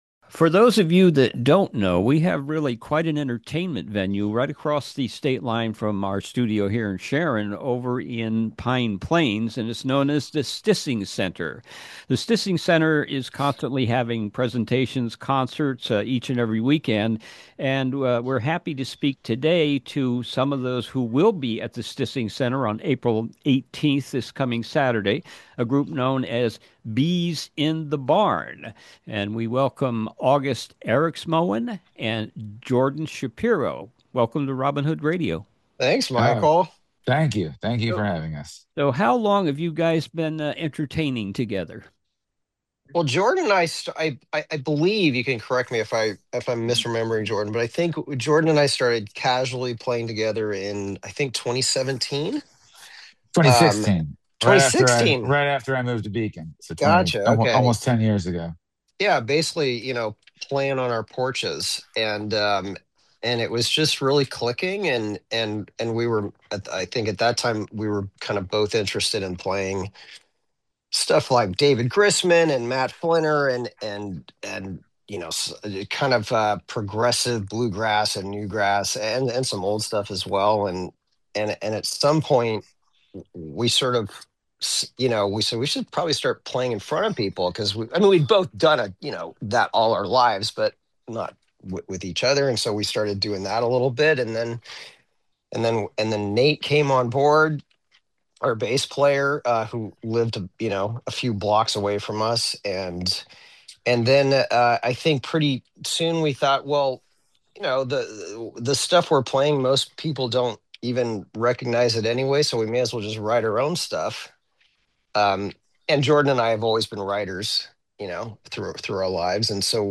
ROBIN HOOD RADIO INTERVIEWS